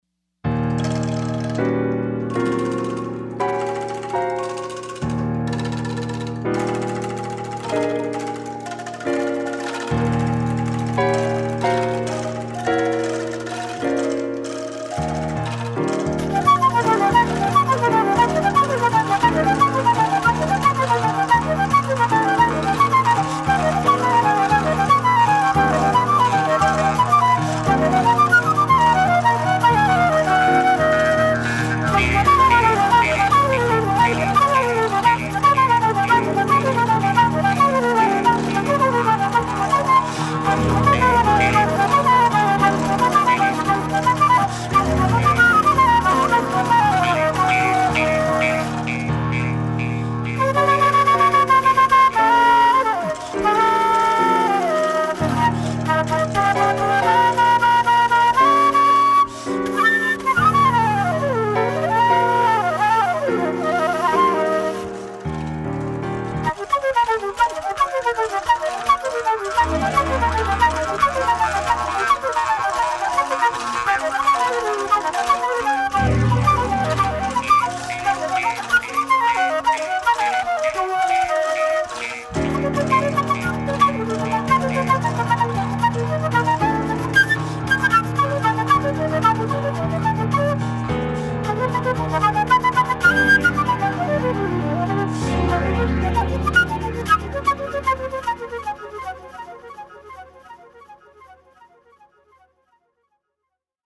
1172   01:40:00   Faixa:     Jazz